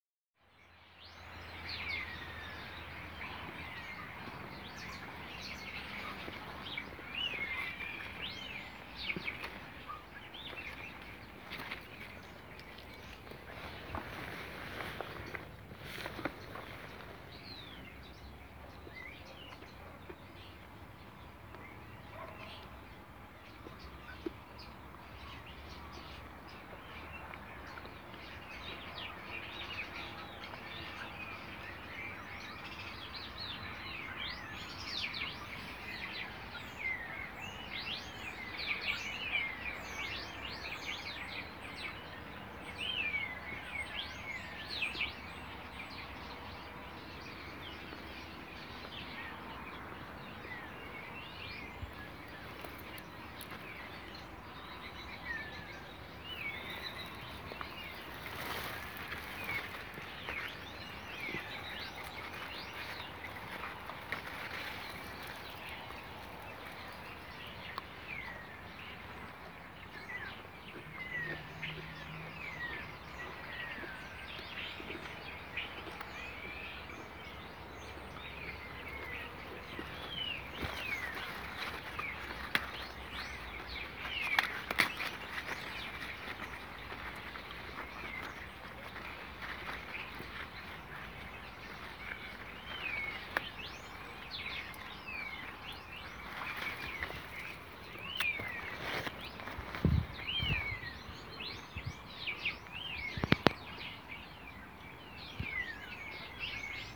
Dann gab es noch die freundliche Frau in der Kirche in Vialles, die mir eine Kerze schenkte und auch das Vogelkonzert im Feigenbaum war wunderschön: